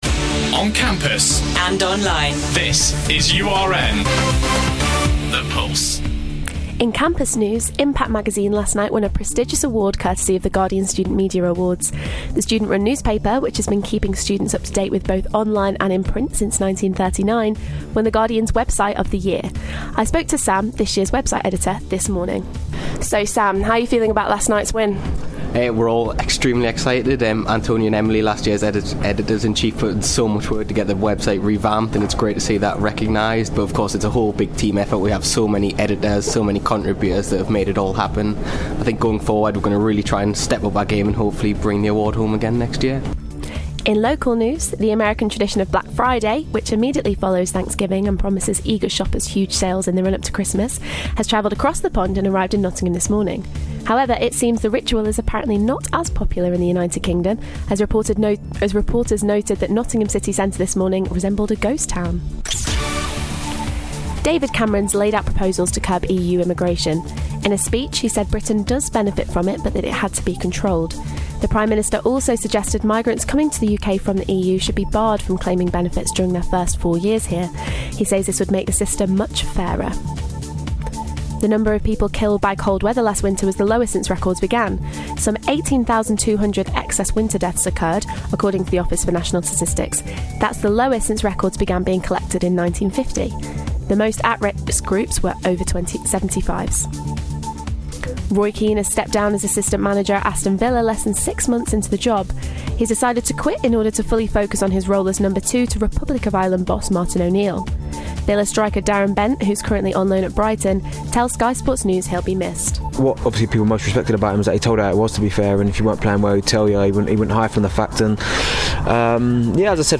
Your latest Headlines for November 28th